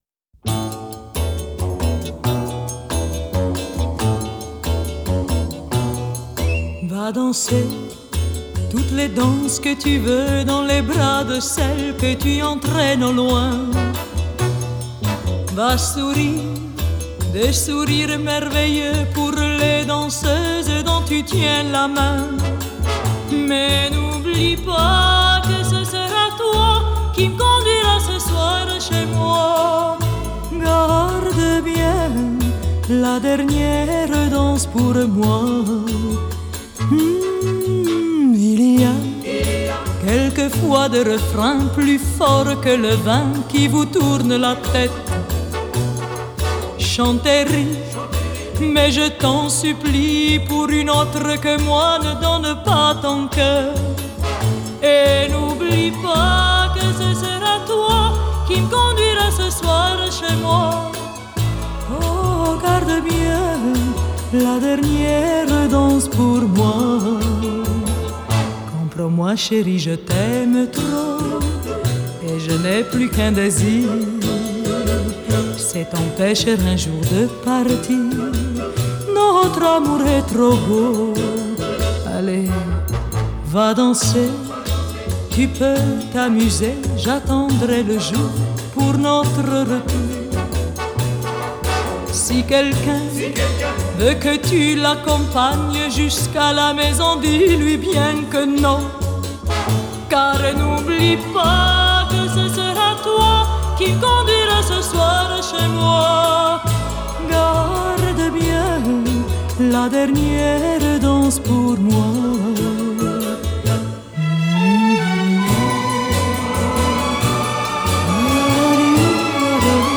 Genre: Variete Francaise, Pop Vocale